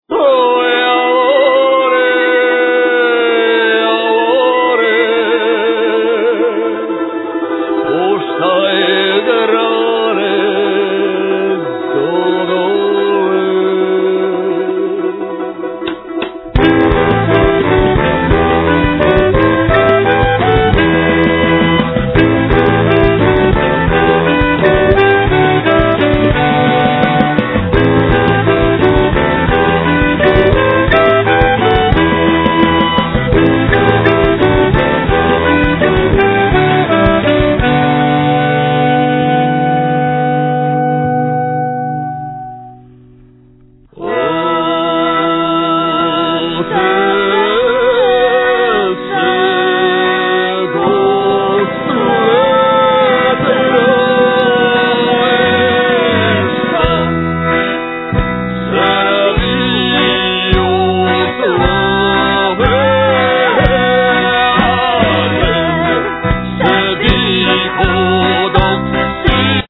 Klarinet, Alt saxophone
Accordion
Vocals
Guitar, Balalaika
Violin